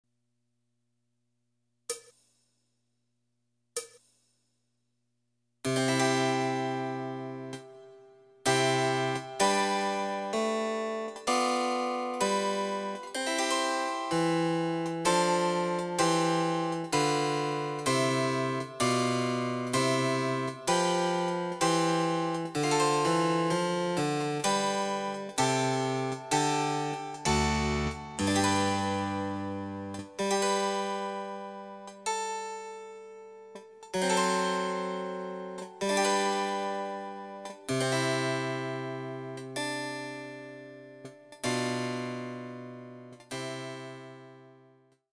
その他の伴奏
Electoric Harpsichord